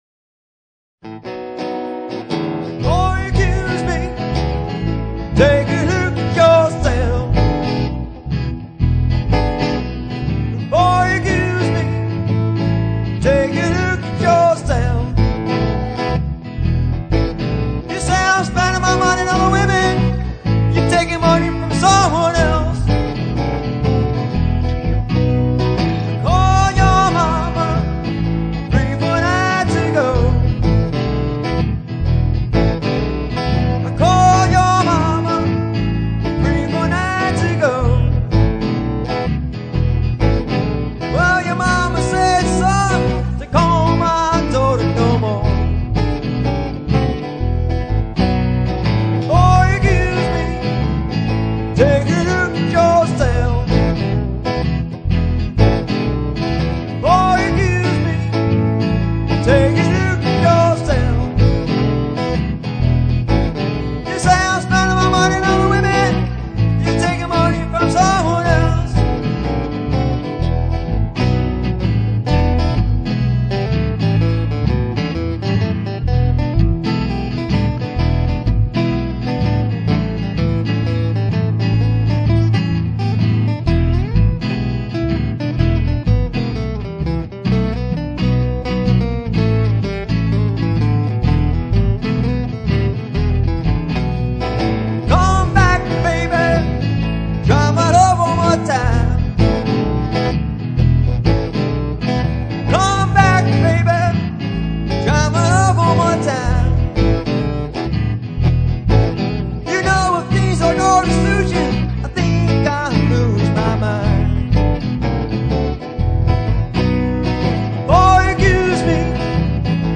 lead guitar
vocals, harmonica, guitar
vocals, guitar, percussion
bass guitar
unplugged classic rock and blues band